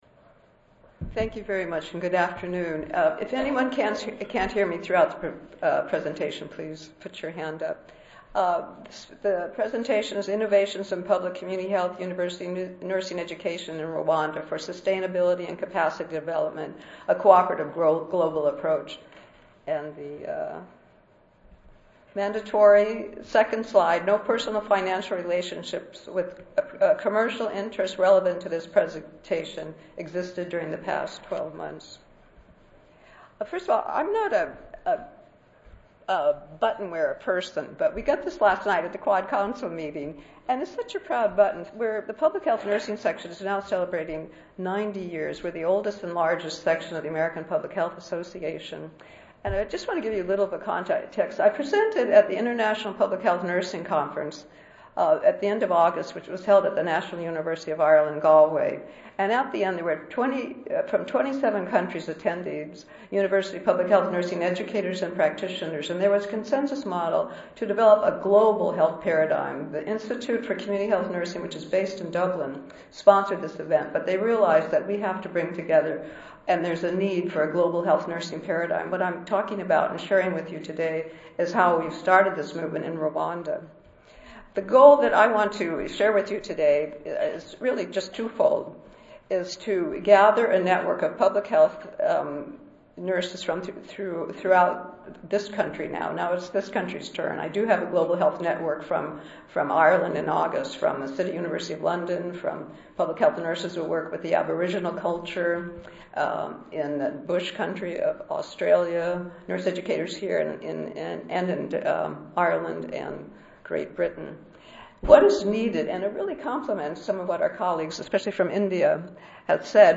141st APHA Annual Meeting and Exposition (November 2 - November 6, 2013): Academic and Community Partnerships: Think Global